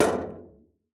VoxelEngine / res / content / base / sounds / steps / metal_0.ogg
metal_0.ogg